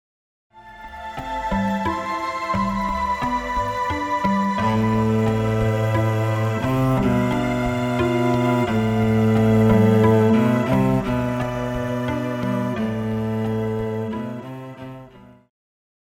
Pop
Cello
Band
Instrumental
World Music,Electronic Music
Only backing